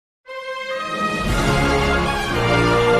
Water Splash
Water Splash is a free sfx sound effect available for download in MP3 format.
064_water_splash.mp3